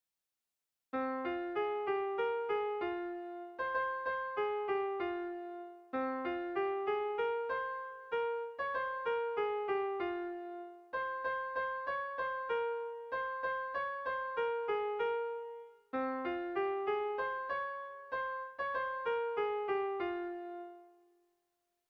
Erromantzea
Zortziko txikia (hg) / Lau puntuko txikia (ip)
A1A2BA2